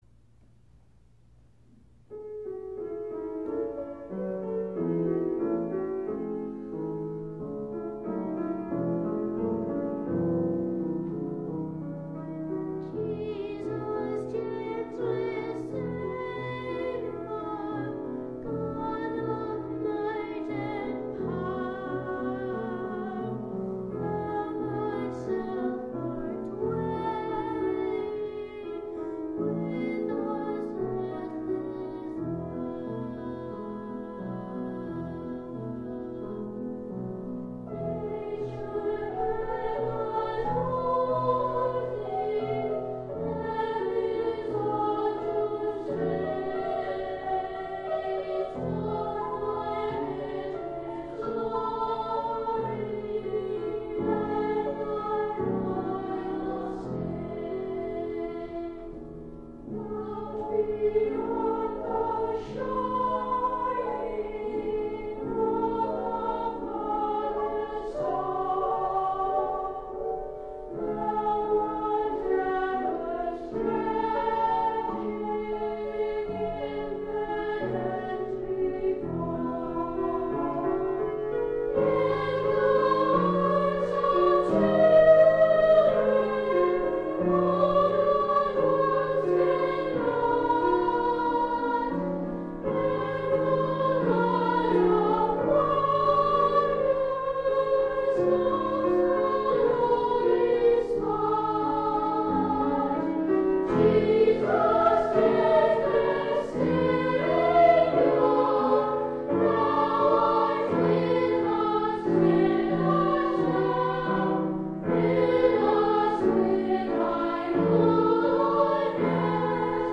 unison voices and keyboard
A simple melody is supported by a flowing accompaniment.
Youth Choir Accompaniment Piano